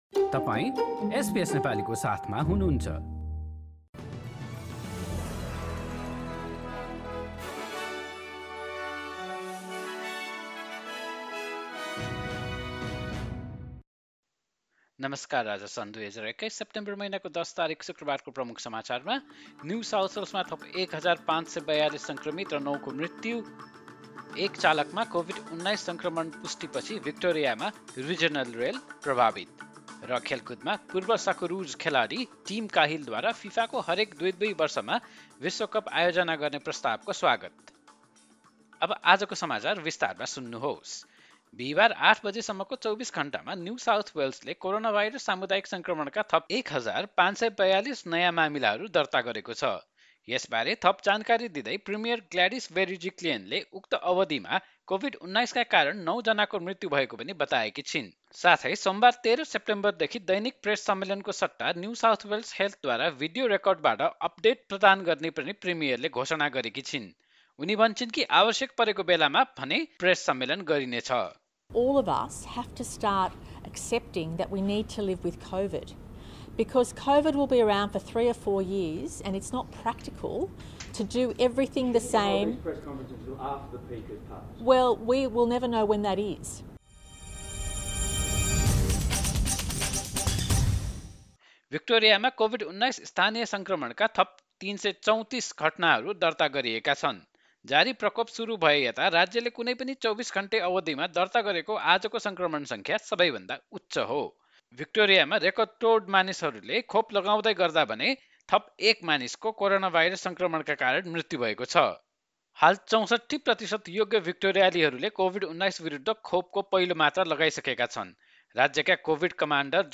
एसबीएस नेपाली अस्ट्रेलिया समाचार: शुक्रवार १० सेप्टेम्बर २०२१